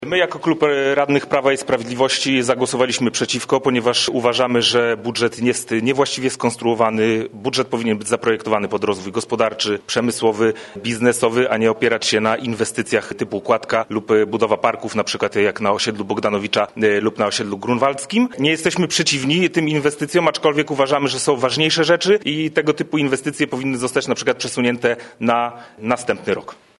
Swoje stanowisko skomentowali także radni Prawa i Sprawiedliwości.